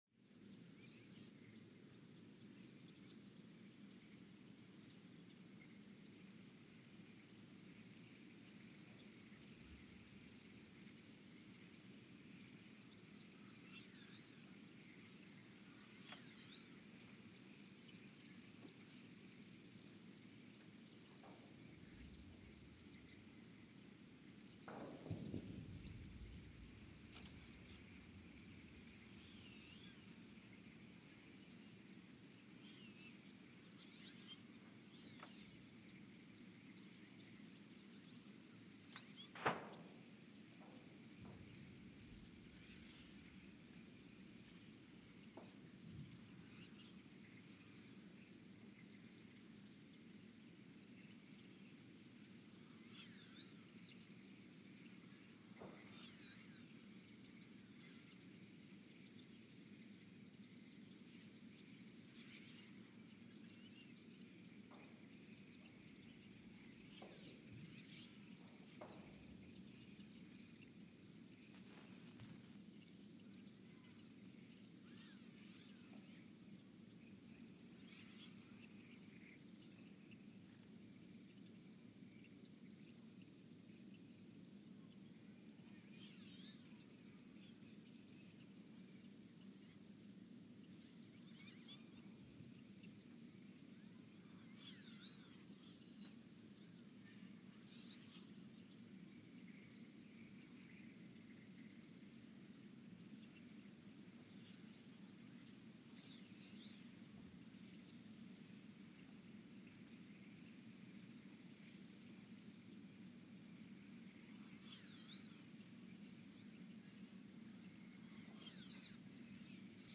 The Earthsound Project is an ongoing audio and conceptual experiment to bring the deep seismic and atmospheric sounds of the planet into conscious awareness.
About this archived stream: Station : ULN (network: IRIS/USGS ) at Ulaanbaatar, Mongolia Sensor : STS-1V/VBB Recorder : Quanterra QX80 @ 20 Hz Pod : ms-urania